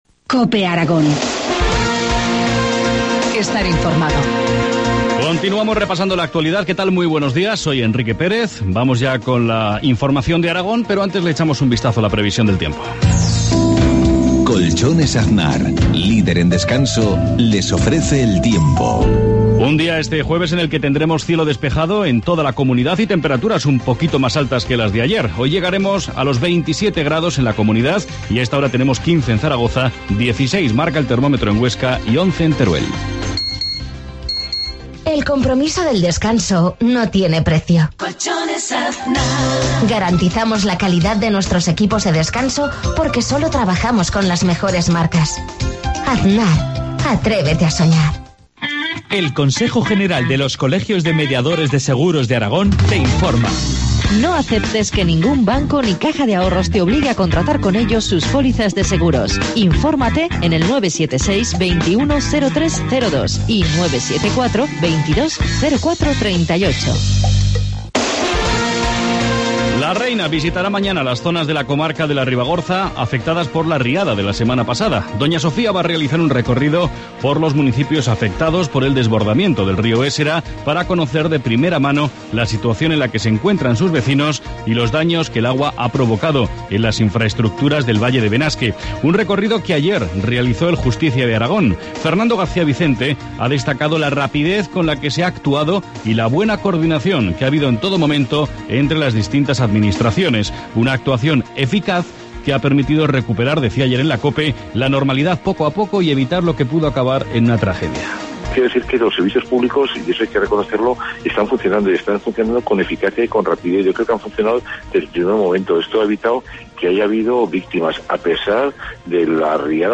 Informativo matinal, 27 junio,7,53 horas